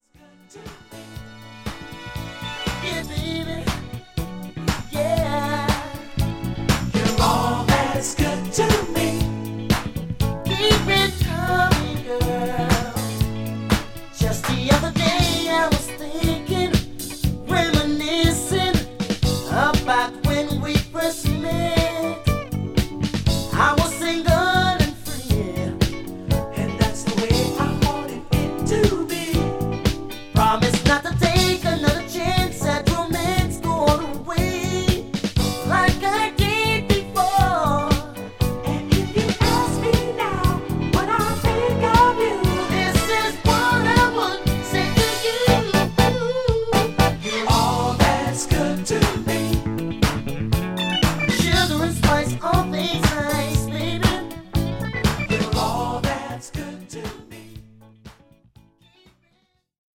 ホーム ｜ SOUL / FUNK / RARE GROOVE / DISCO > SOUL
A1,2、B1,4といったモダン・ファンク〜ダンサー・チューンが充実したアルバムです。